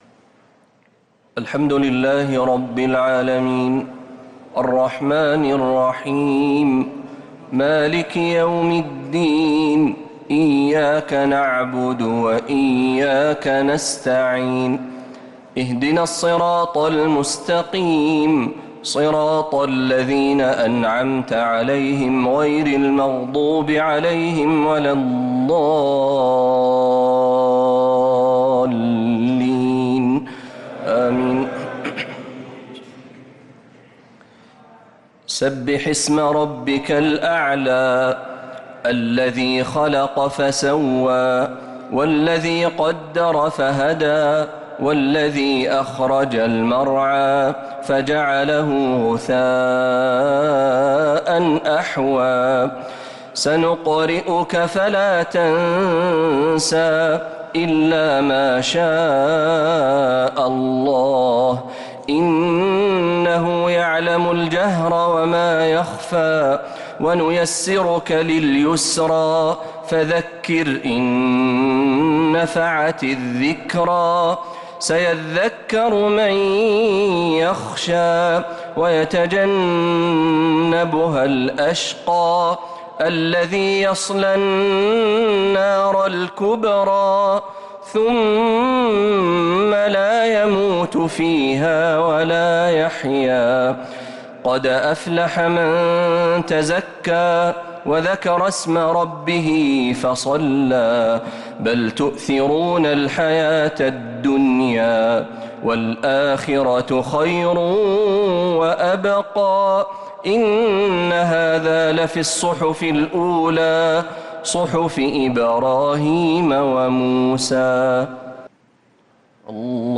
الشفع والوتر ليلة 19 رمضان 1446هـ | Witr 19th night Ramadan 1446H > تراويح الحرم النبوي عام 1446 🕌 > التراويح - تلاوات الحرمين